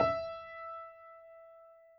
piano_064.wav